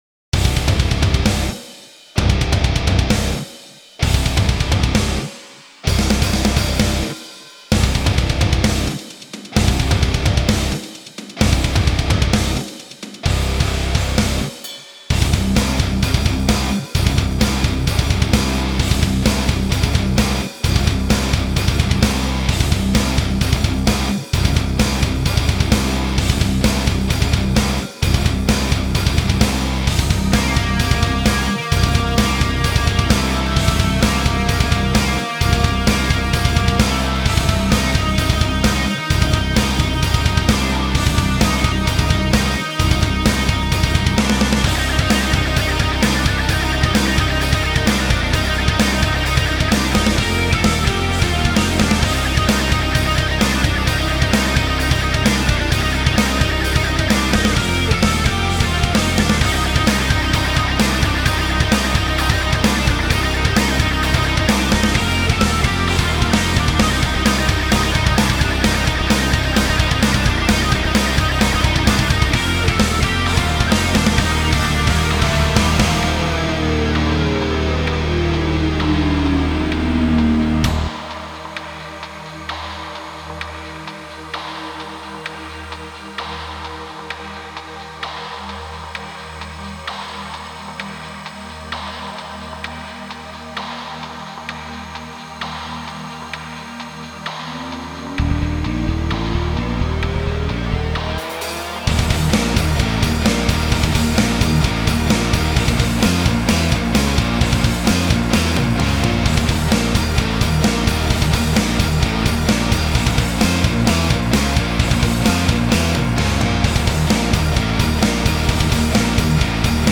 [Industrial Psychedelic Metal] Ревизия старого трека
Наверняка многие услашат что мало низа, но на данный момент контроль большего не позволяет. И ещё, никак не могу придумать как вписать томы в микс - совсем выпадают, по-моему.